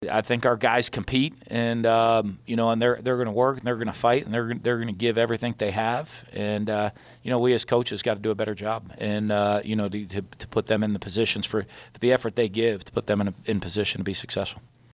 Like it was for the whole week of practice, head coach Dan Mullen had a press conference, but kept players and other coaches out of media availability.